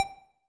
TEC Blip 1 G.wav